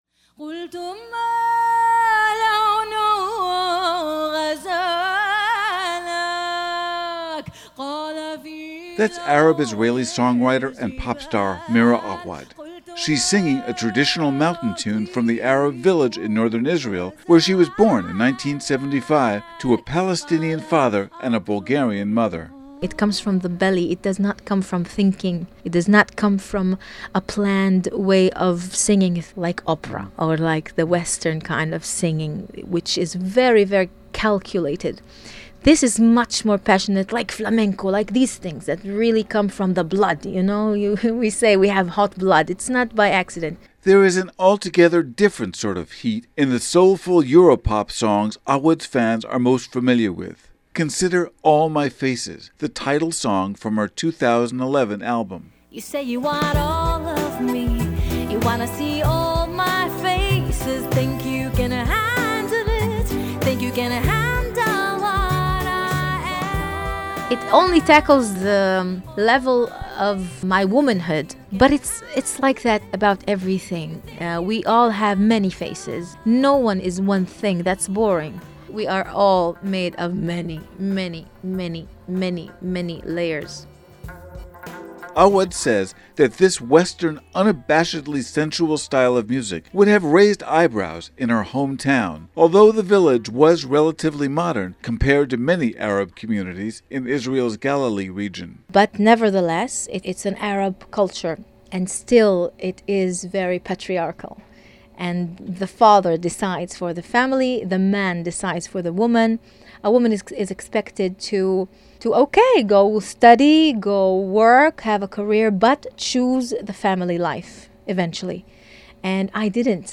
NEW YORK —  Arab-Israeli songwriter and pop star Mira Awad began her weeklong run at New York’s Metropolitan Room by singing a traditional tune from the Arab village in northern Israel where she was born in 1975 to a Palestinian father and a Bulgarian mother.
There is a different sort of heat in the soulful Europop songs Awad’s fans are most familiar with.
Awad says this Western, unabashedly sensual style of music would raise eyebrows in her hometown, although the village is relatively modern compared to many Palestinian communities in Israel’s Galilee region.